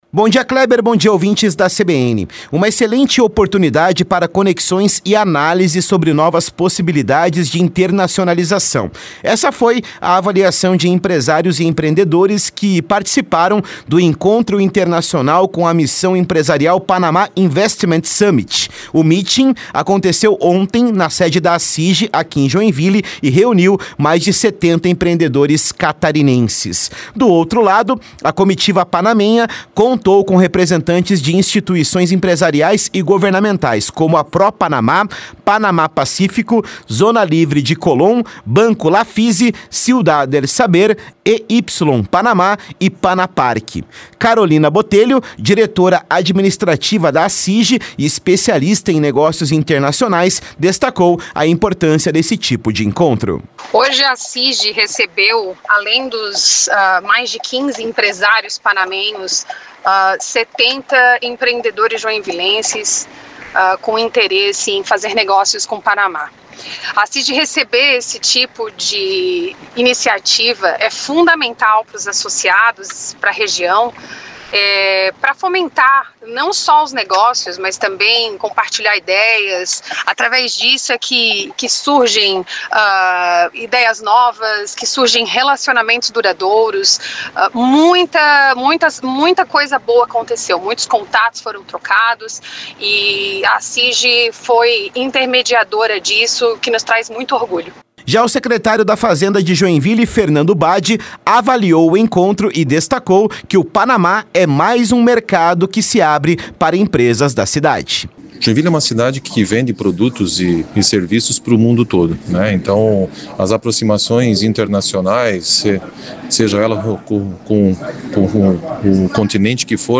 CLIQUE AQUI para ouvir reportagem da Rádio CBN com avaliações da ACIJ, da Prefeitura de Joinville, da entidade que representa os empresários do Panamá e do representante do governo panamenho.